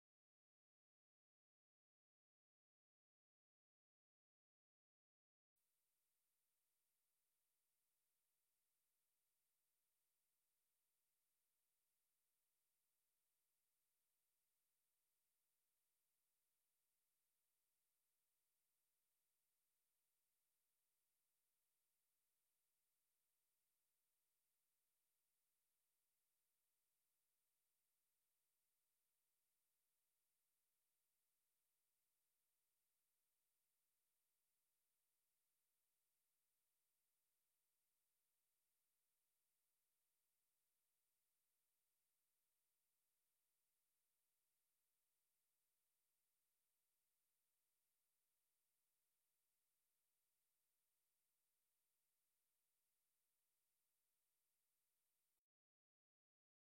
Aufnahmen aus einem Geschäft in Tokmak in Zaporizhzhia.